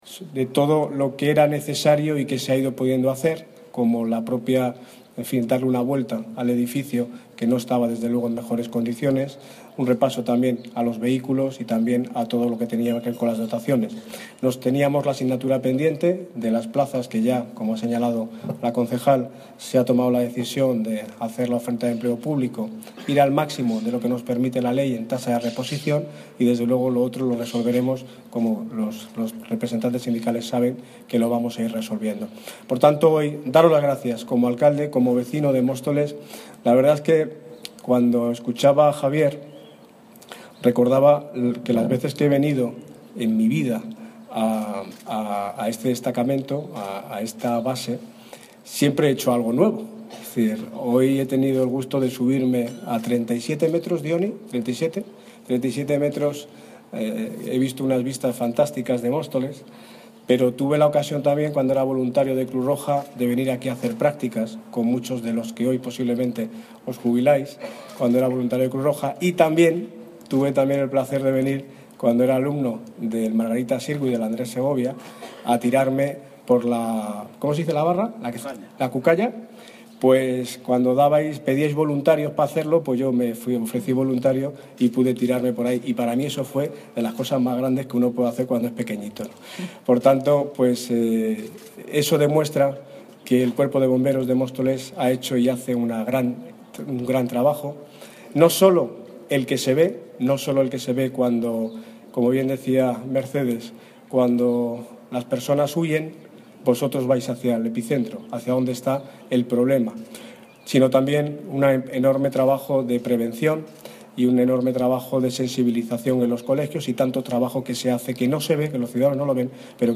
Audio - Daniel Ortiz (Alcalde de Móstoles) Sobre Patron Bomberos